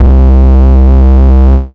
Fat Upright Bass (JW2).wav